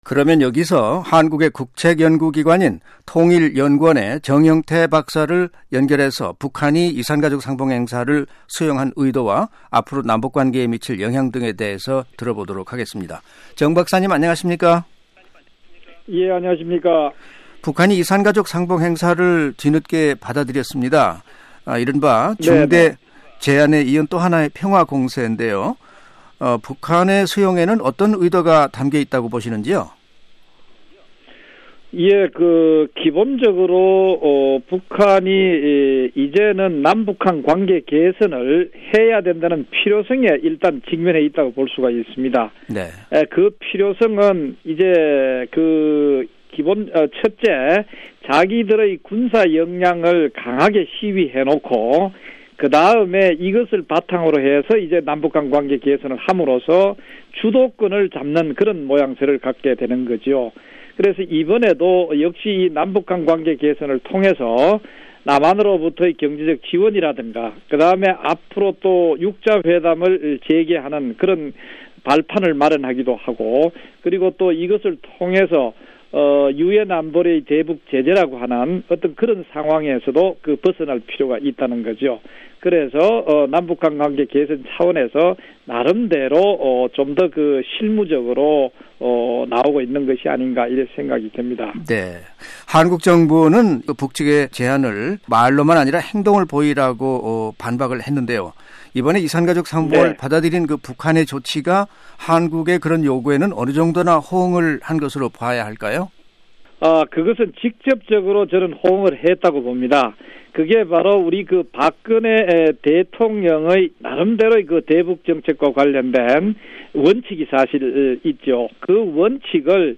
[인터뷰